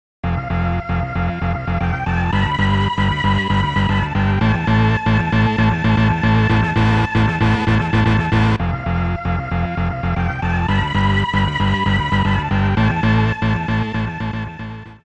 ※一部の楽曲に収録の都合によりノイズが入る箇所があります。